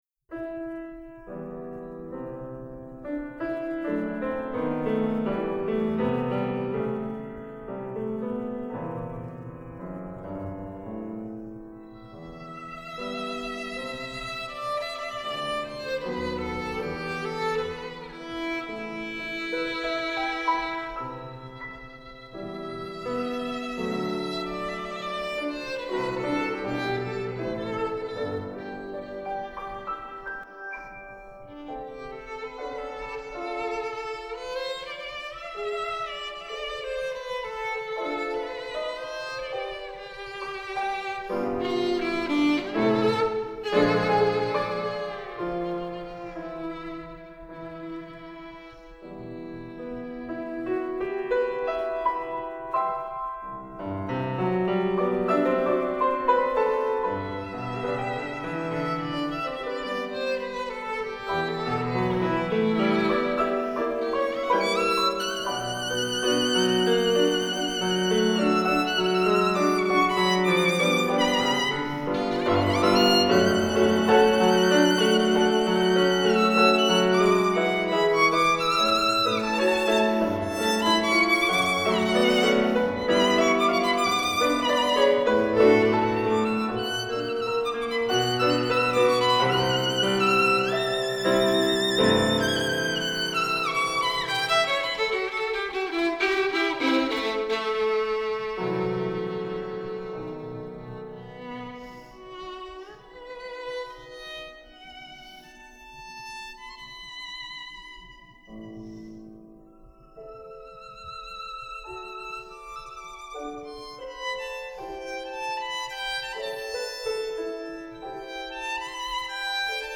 7Bughici_Lento.mp3